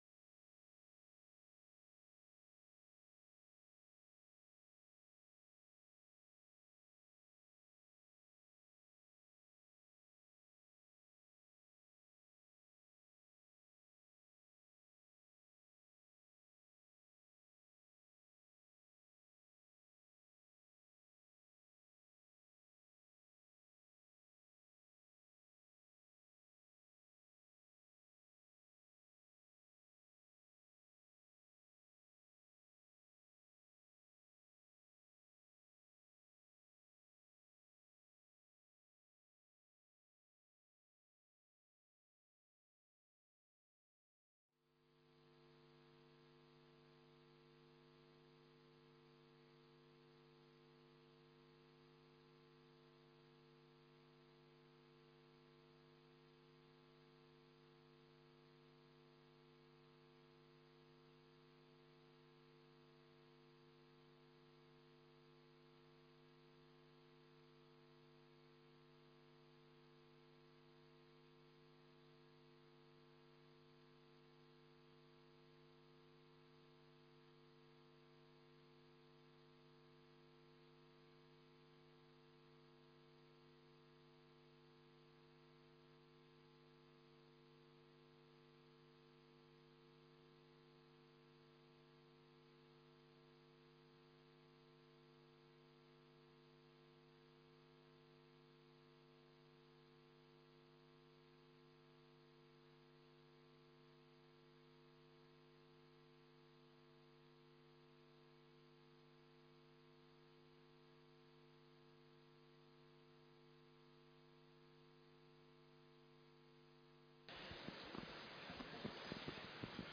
محاضرة سلطنة عمان